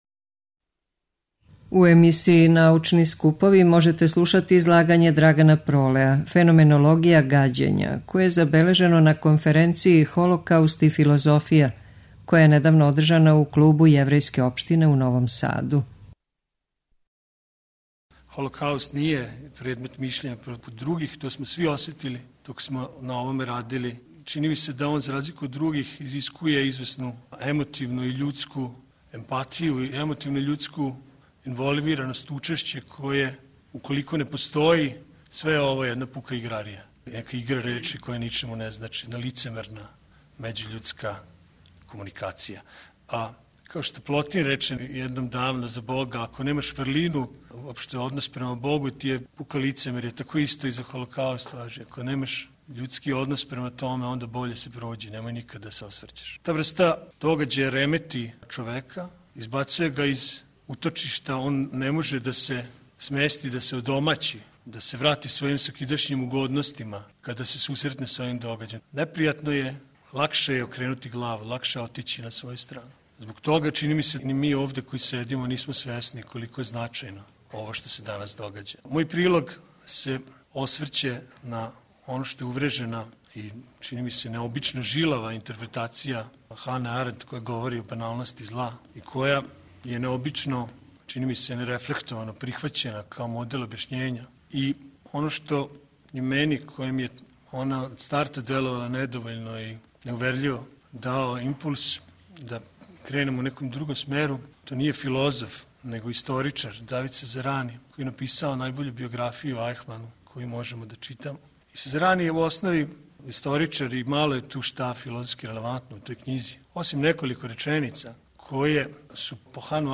преузми : 6.02 MB Трибине и Научни скупови Autor: Редакција Преносимо излагања са научних конференција и трибина.